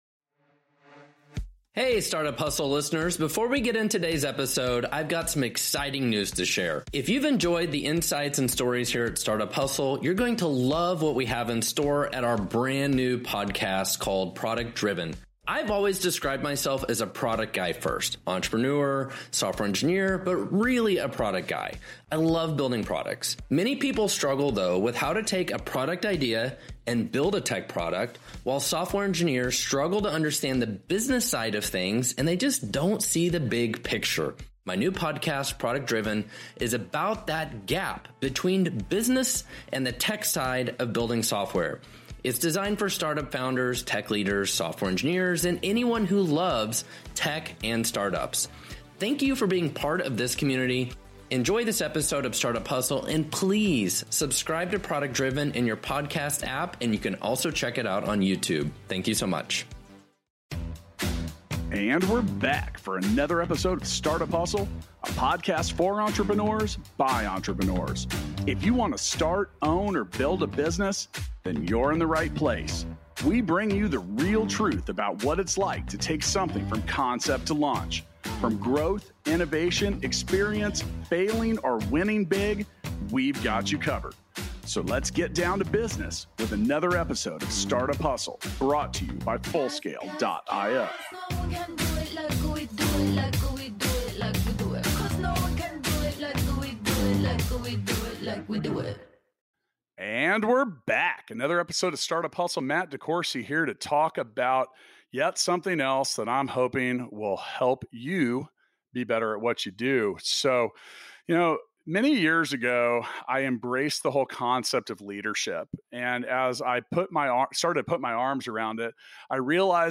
a conversation about self-leadership